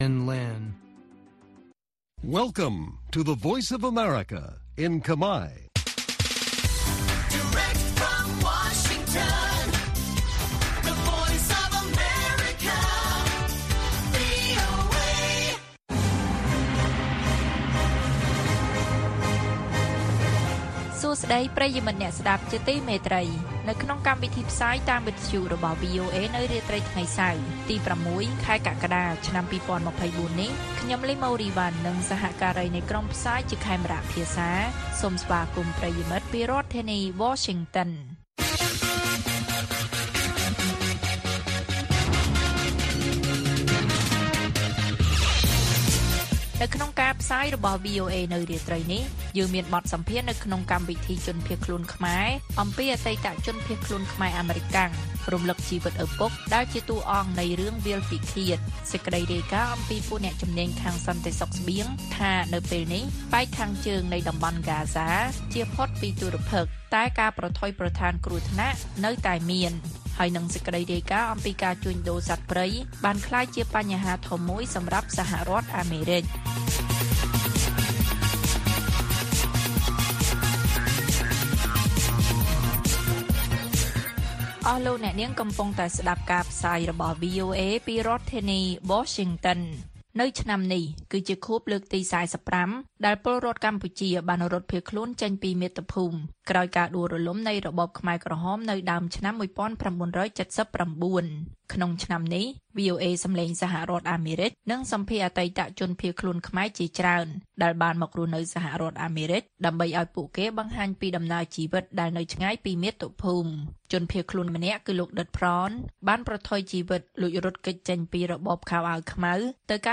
ព័ត៌មាននៅថ្ងៃនេះមានដូចជា បទសម្ភាសន៍នៅក្នុងកម្មវិធីជនភៀសខ្លួនខ្មែរ៖ អតីតជនភៀសខ្លួនខ្មែរអាមេរិកាំងរំឭកជីវិតឳពុកដែលជាតួអង្គនៃរឿង«វាលពិឃាត»។ ការជួញដូរសត្វព្រៃបានក្លាយជាបញ្ហាធំមួយសម្រាប់សហរដ្ឋអាមេរិក និងព័ត៌មានផ្សេងៗទៀត៕